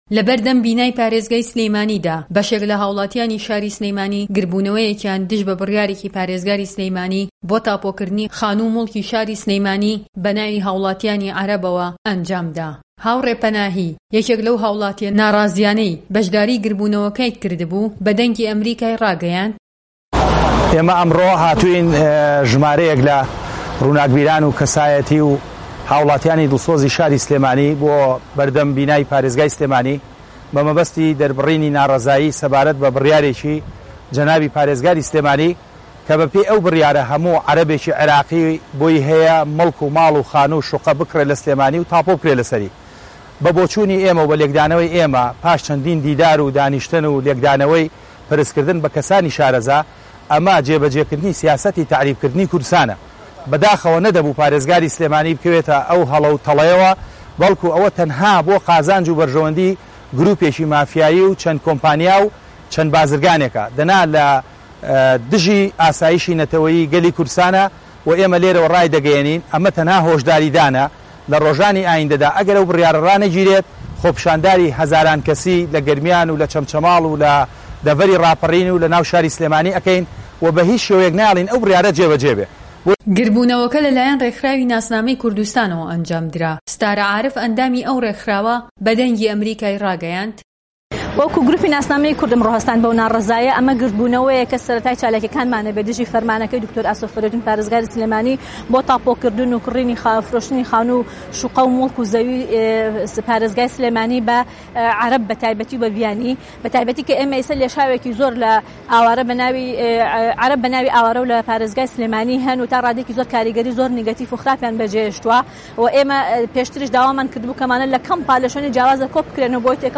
خۆپیشاندانی ناڕەزایی